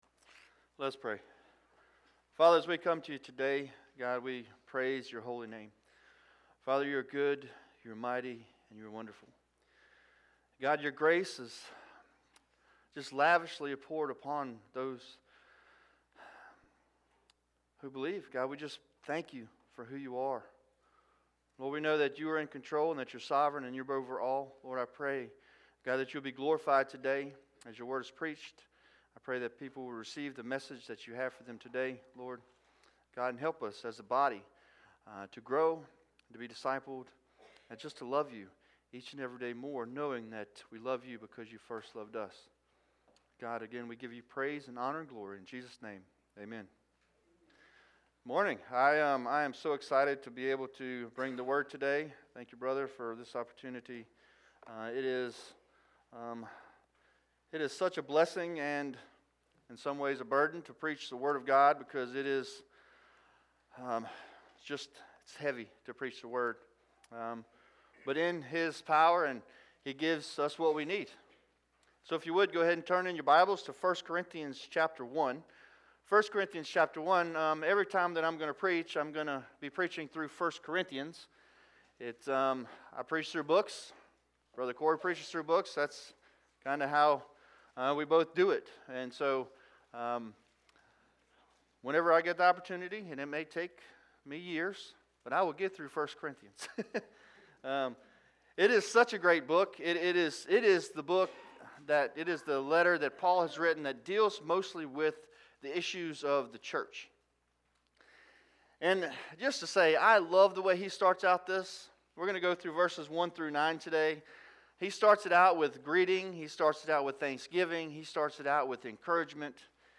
Sermons | Evans Creek Baptist Church